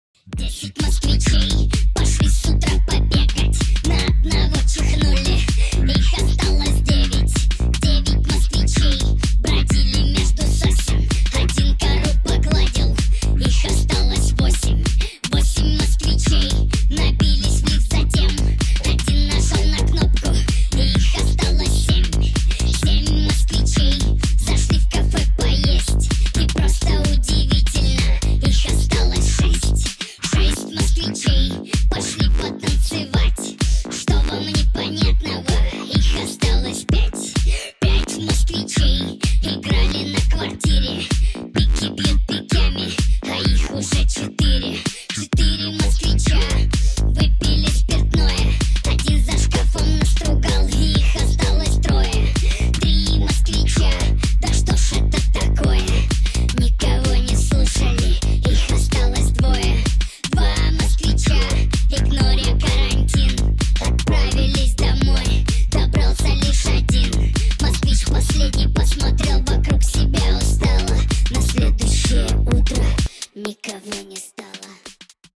• Качество: 320, Stereo
мужской голос
веселые
электронная музыка
house
смешной голос